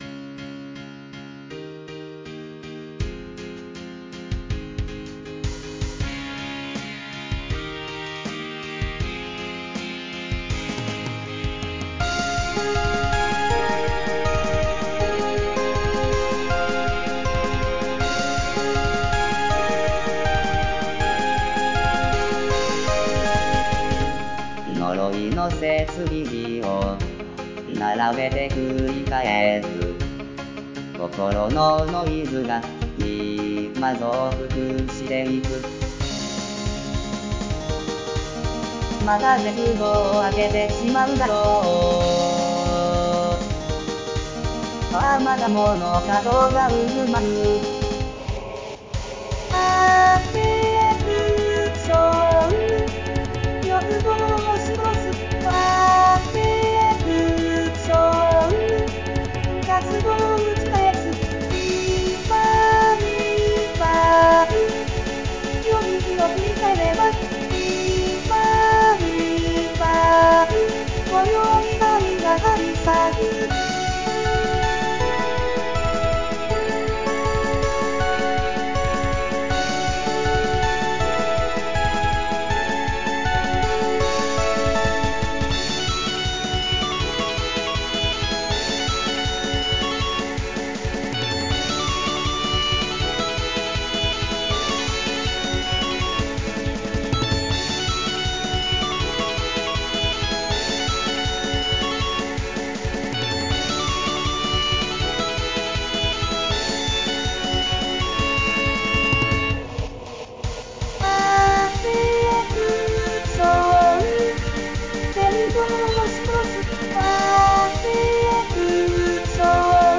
独唱